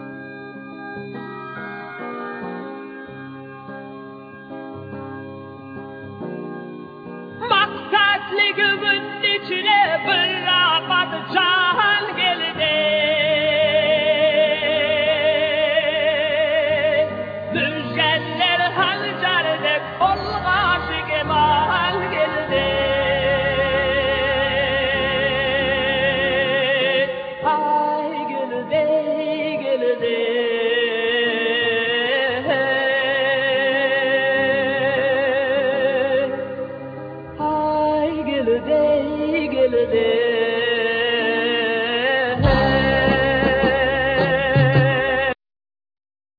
Keyboards
Drums,Percussion
Guitar,sitar
Bass,Backing Vocal
Trumpet
Tenor saxophne,Flute
Violin
Lead vocal
Duduk